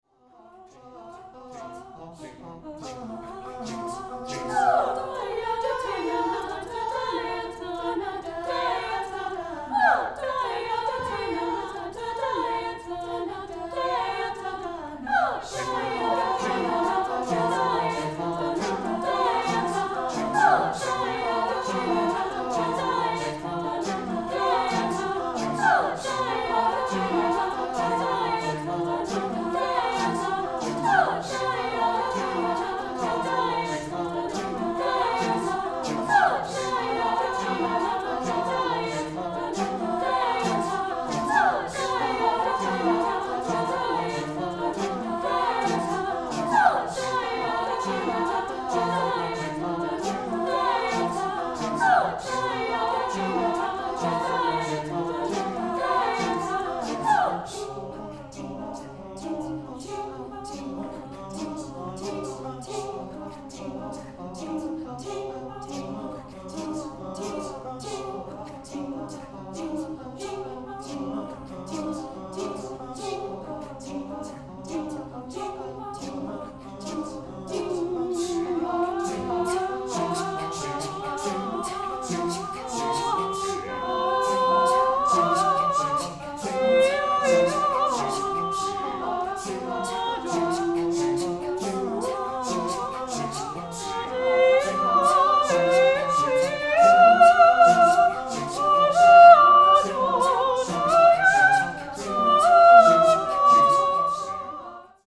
SSAATTB, soprano soloist